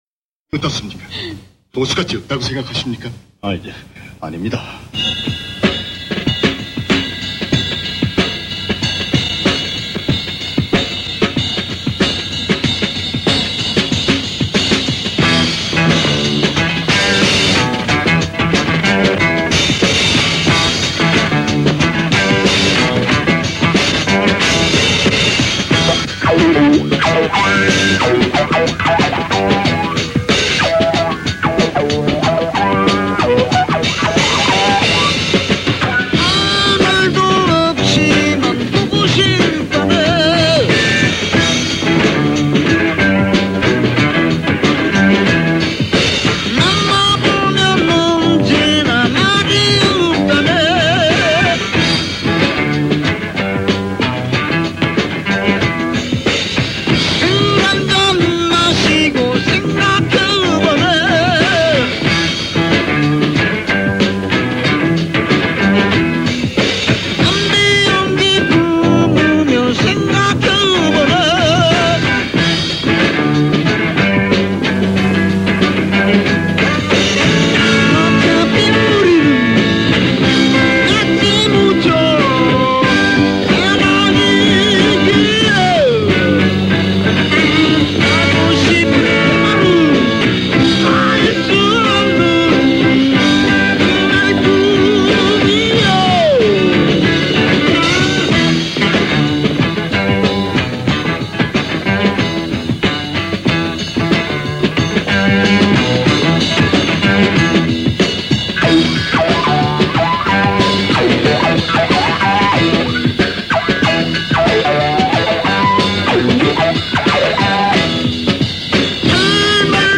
but the shorter, tighter and often funkier tracks